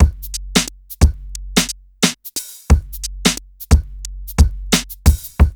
2HF89BEAT1-L.wav